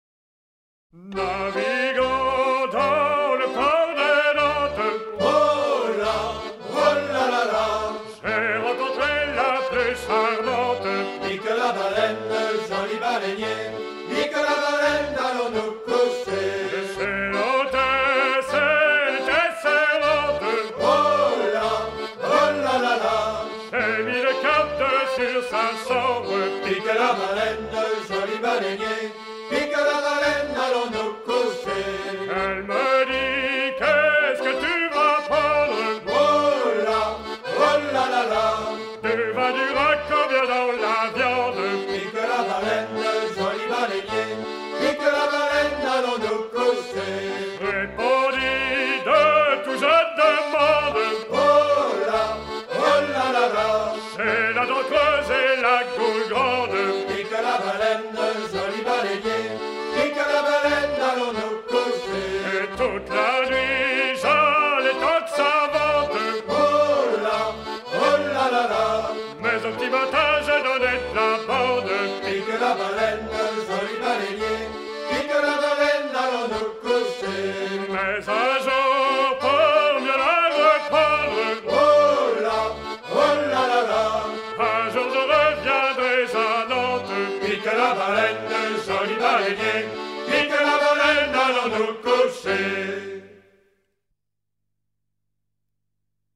auprès de cap-horniers nantais aux régates de Trentemoult vers 1960
Pièce musicale éditée